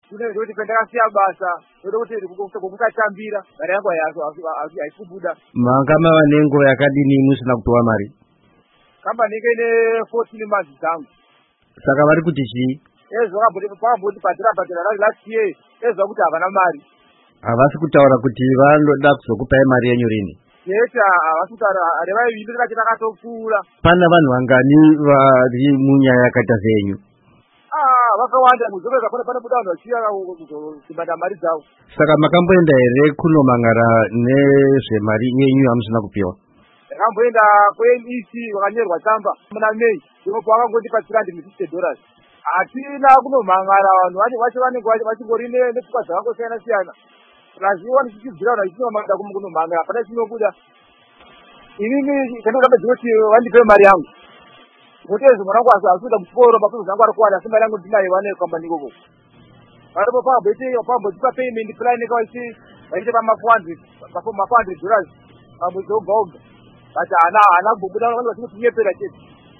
Hurukuro neumwe wevashandi veGuardian Security